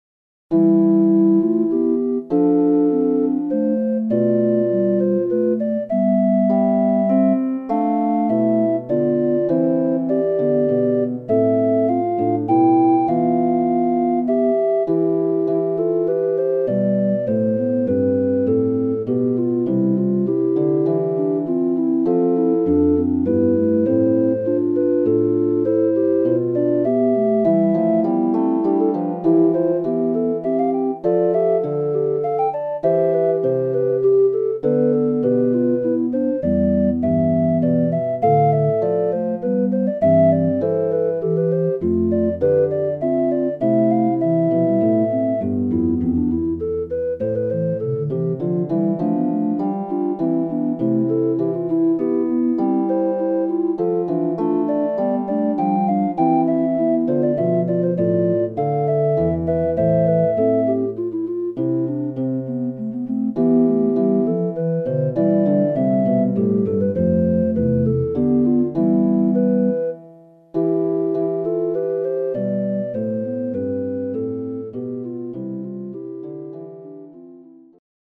Waltz